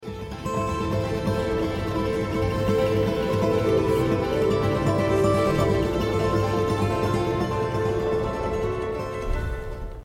A música de fundo é suave, com notas de violino.